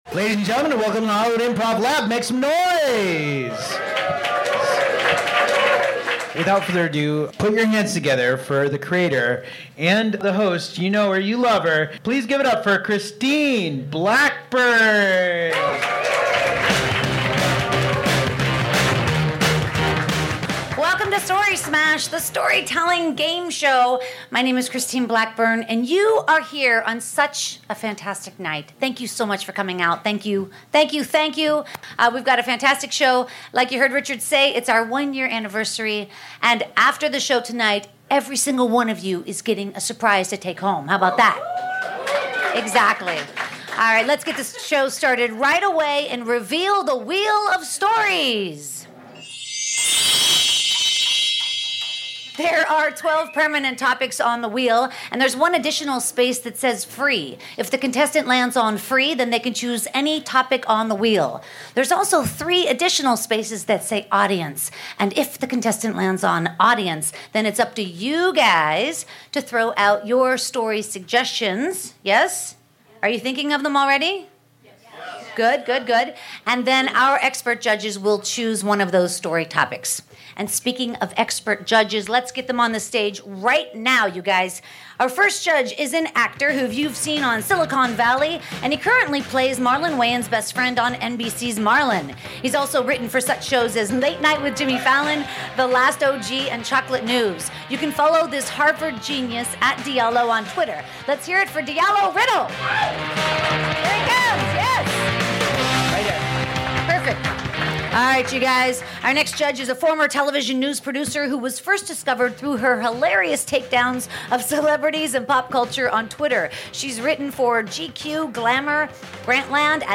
511 - Story Smash The Storytelling Gameshow LIVE at the Hollywood Improv July 28th